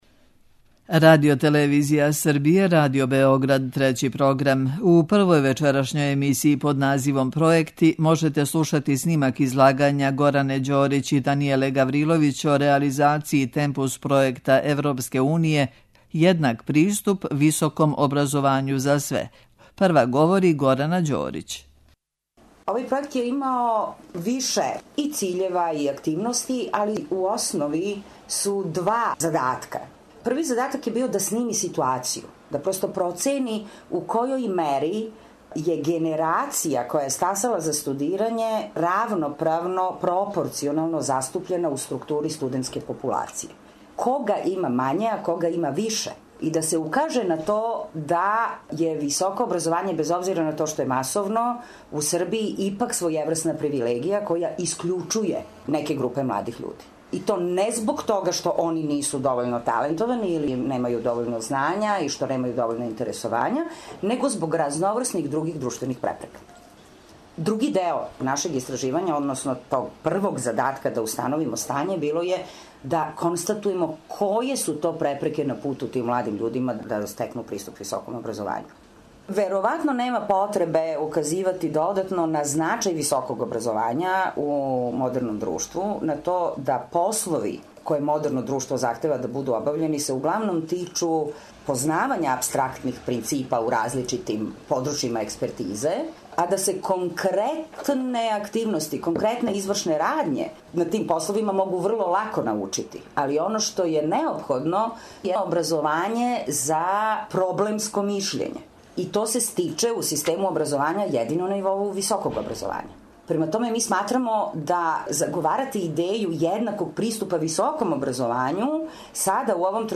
Снимак је забележен у марту на Филозофском факултету у Нишу.
преузми : 14.95 MB Трибине и Научни скупови Autor: Редакција Преносимо излагања са научних конференција и трибина.